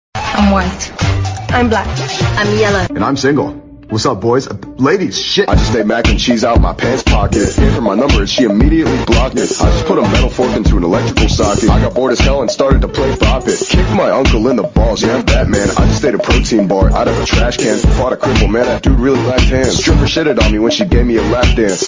im white im black im yellow and im single Meme Sound Effect
This sound is perfect for adding humor, surprise, or dramatic timing to your content.